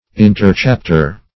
Interchapter \In`ter*chap"ter\, n. An intervening or inserted chapter.